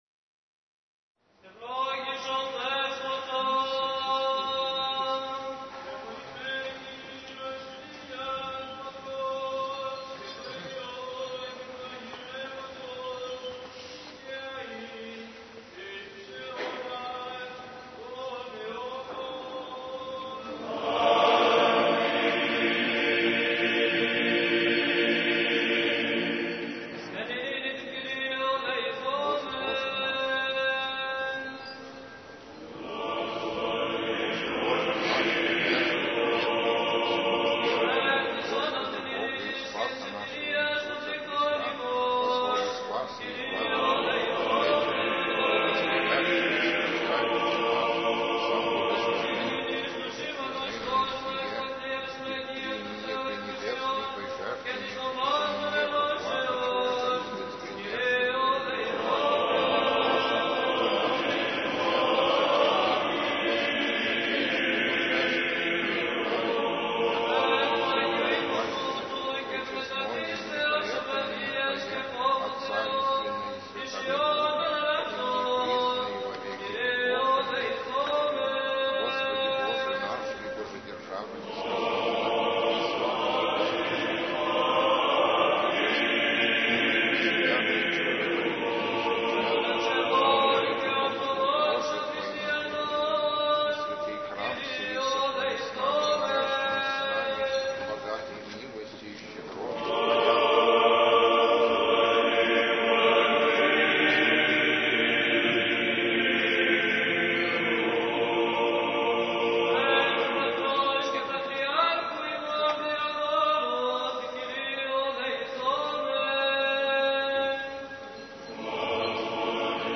Архив mp3 / Духовная музыка / Русская / Патриаршее богослужение /
Литургия у Гроба Господня 3 января 2000 г.